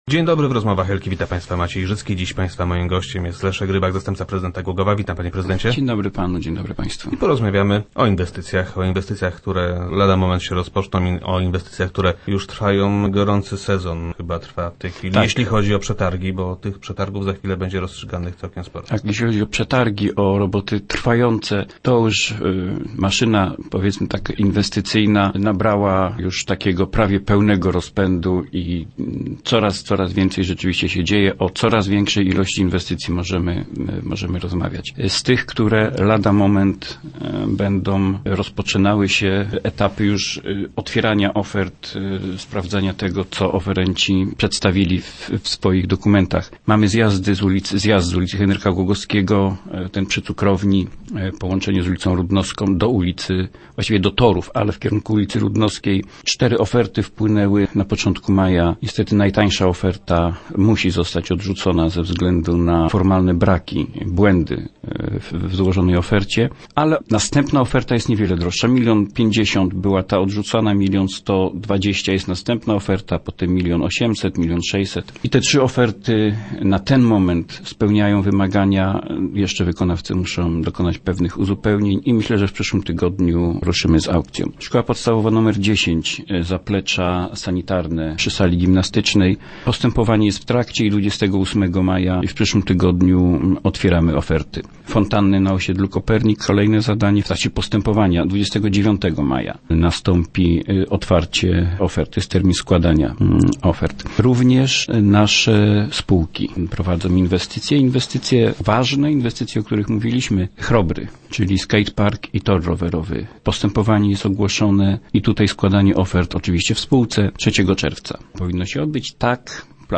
W najbliższym czasie rozstrzygnięte zostaną przetargi na kolejne zadania. - Maszyna inwestycyjna nabrała niemal pełnego pędu – twierdzi Leszek Rybak, zastępca prezydenta Głogowa, który był gościem Rozmów Elki.